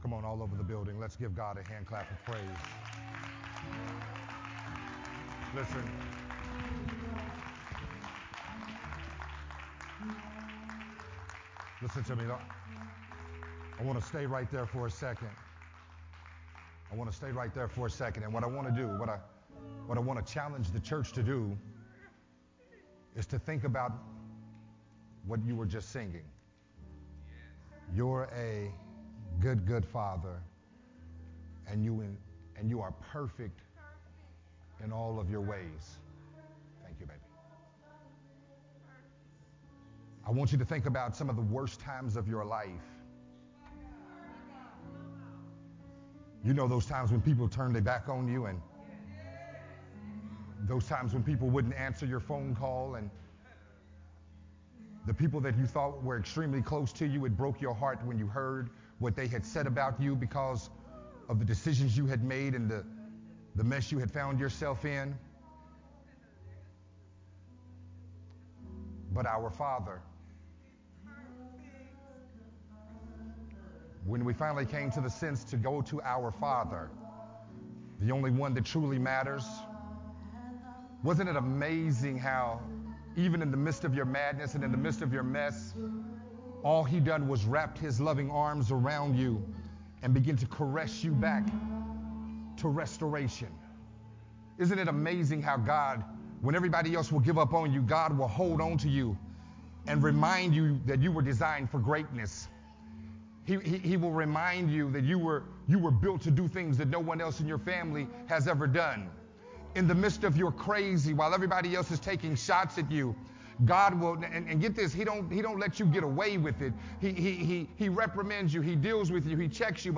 recorded at Unity Worship Center on July 17th, 2022